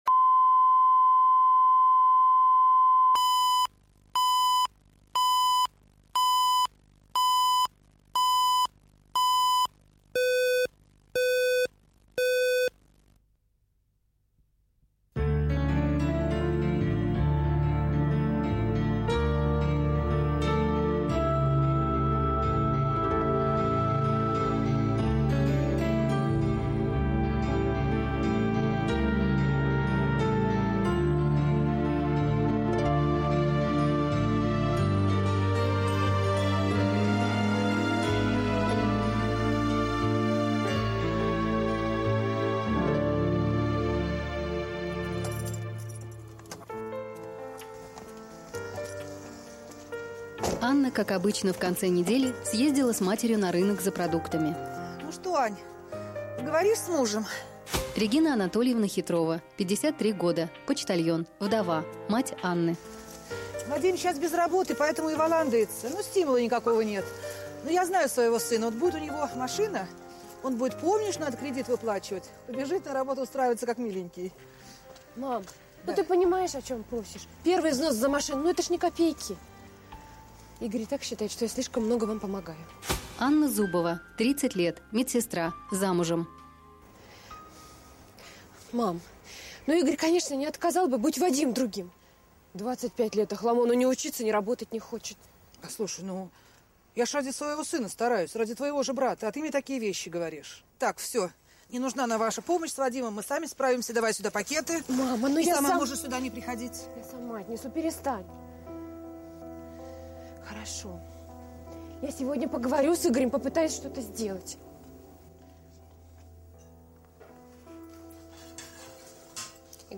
Аудиокнига Дочерний долг